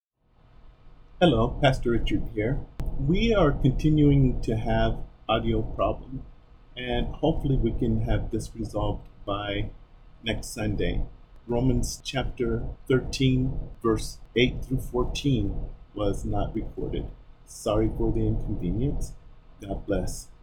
Romans 13:8-14 Recording Problems